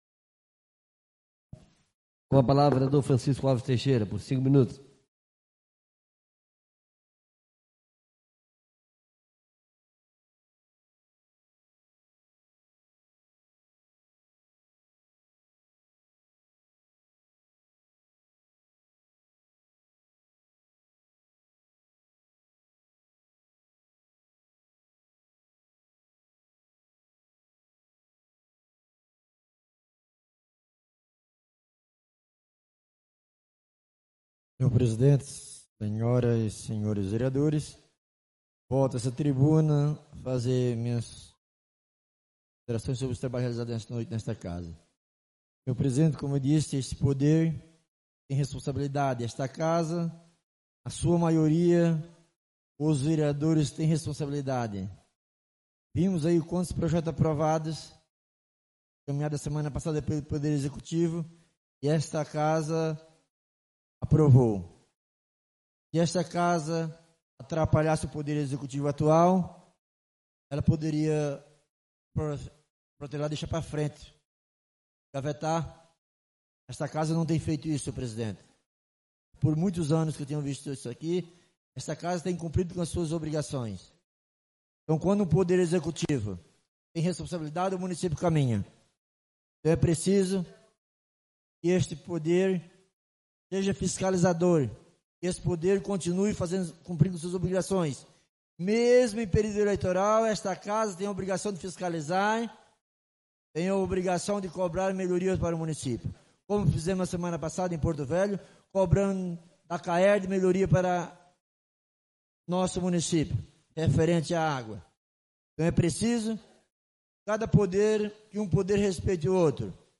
Oradores do Expediente (29ª Ordinária da 4ª Sessão Legislativa da 6ª Legislatura)